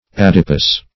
Adipous \Ad"i*pous\